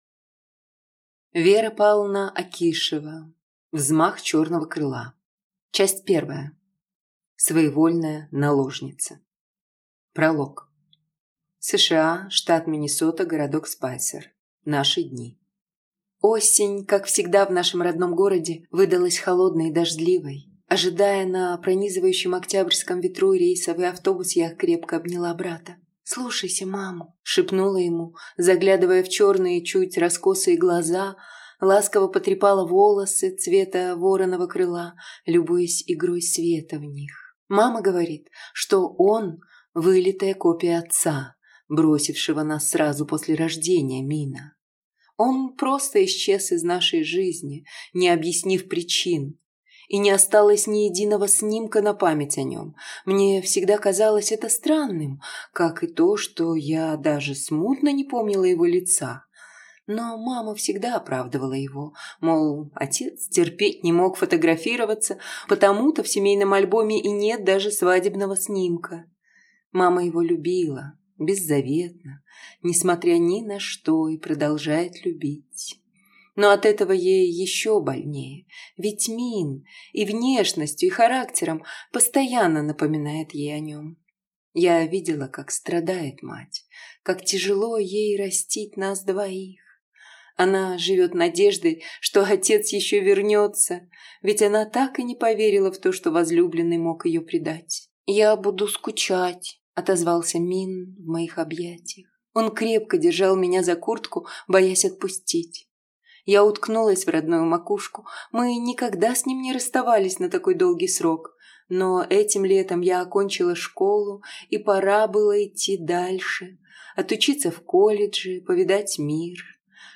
Аудиокнига Взмах черного крыла. Часть 1. Своевольная наложница | Библиотека аудиокниг